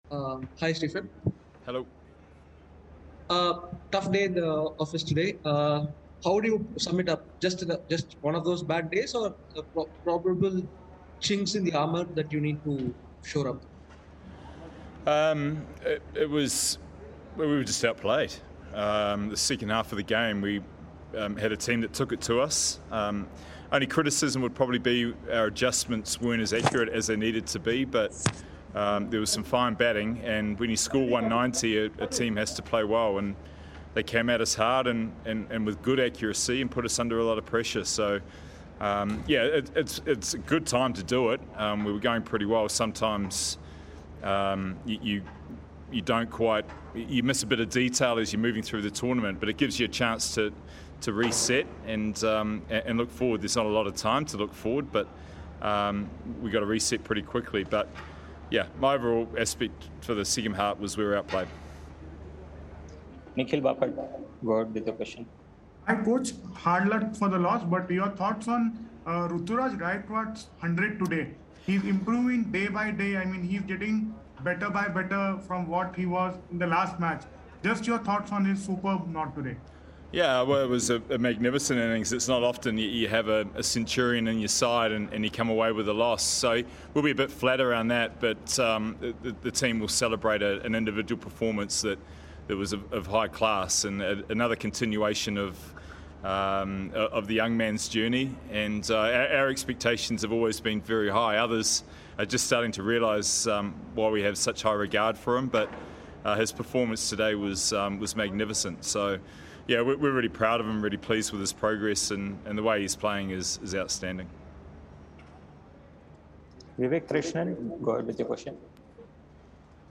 Stephen Fleming of Chennai Super Kings addressed the media after the game.